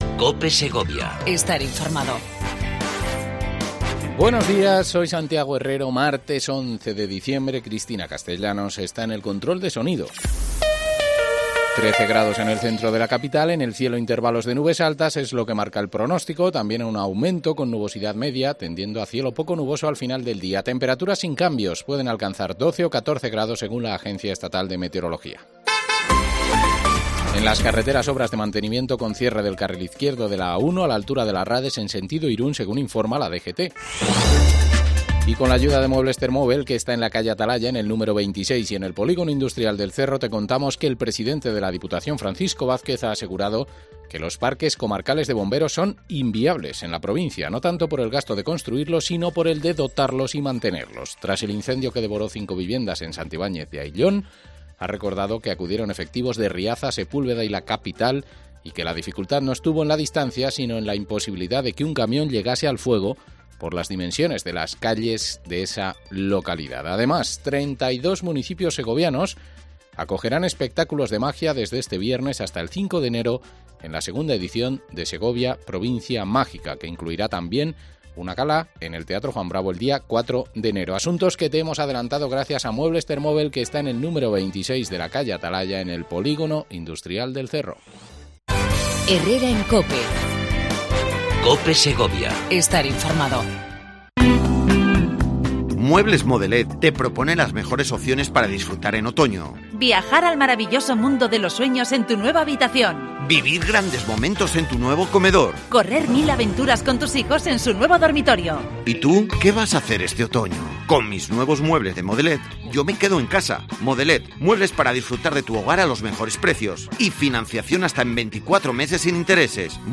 AUDIO: Entrevista a Paloma Maroto, Concejala de Obras, Servicios e Infraestructuras de la capital segoviana